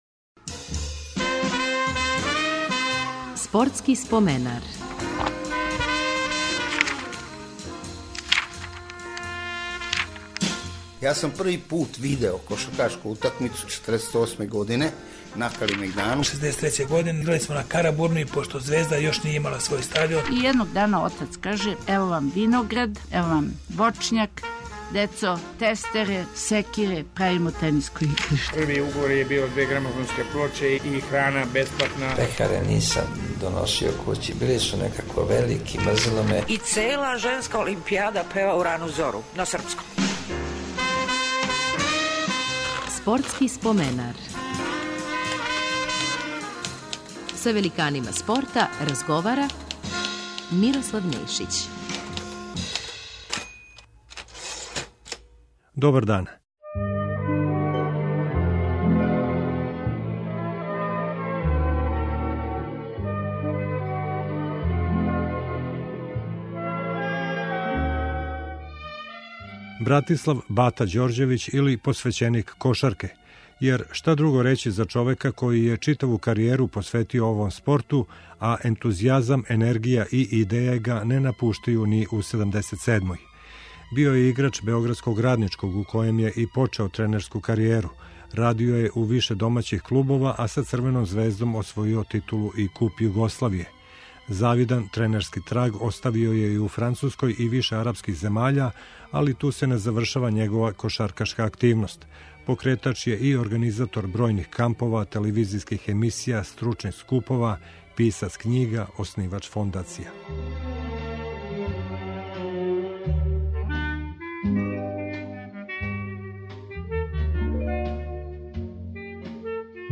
Гост ће нам бити кошаркашки тренер